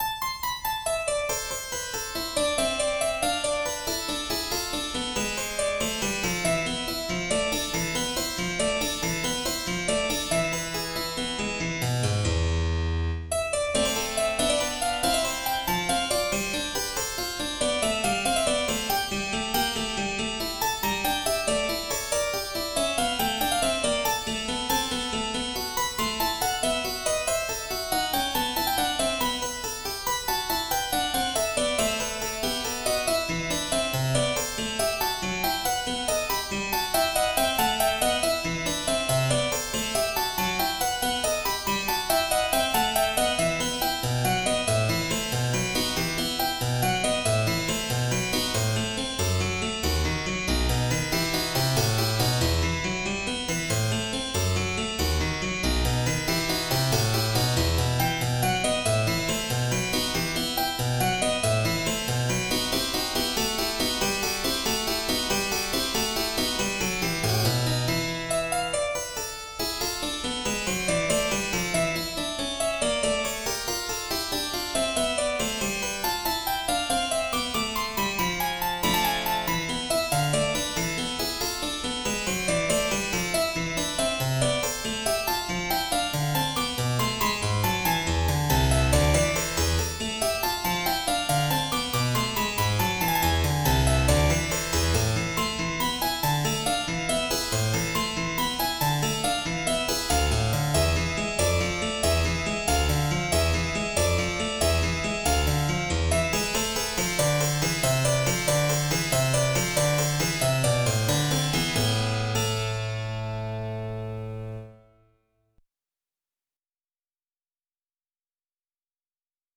in A minor: Vivo